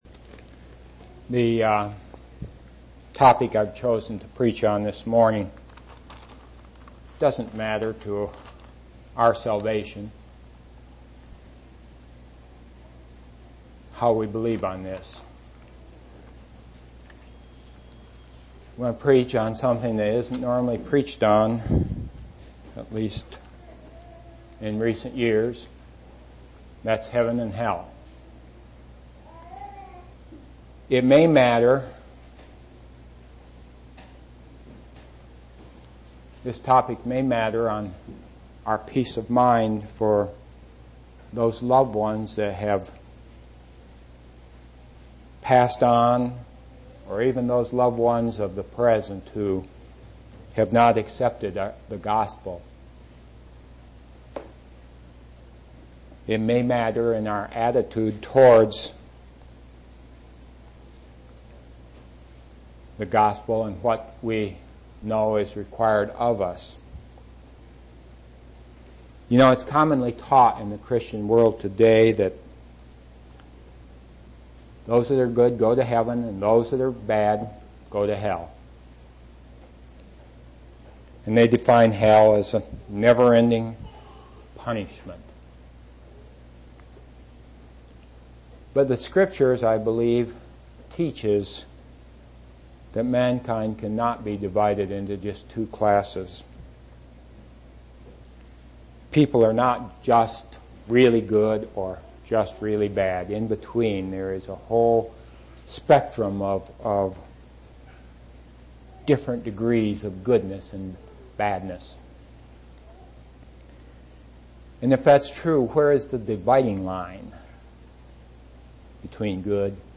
7/28/1991 Location: East Independence Local Event